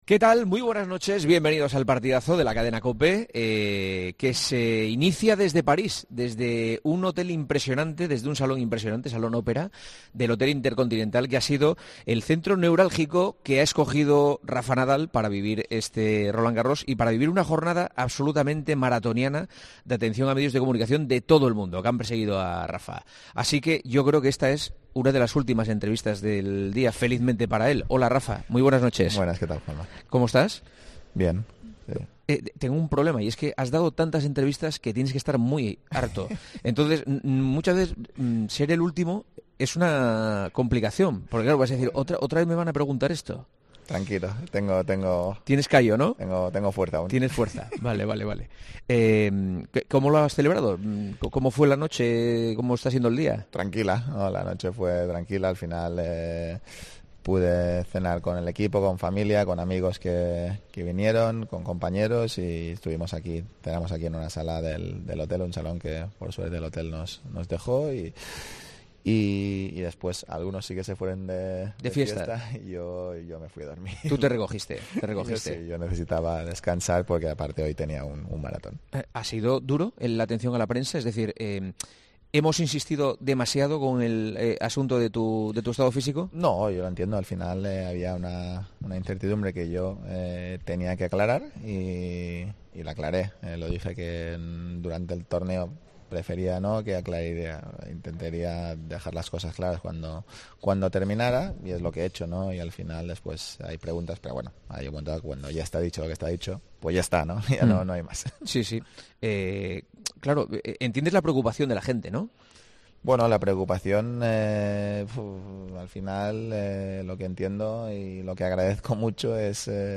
AUDIO: El tenista español compartió sus sensaciones con Juanma Castaño el día después de sumar el 22º Grand Slam de su carrera.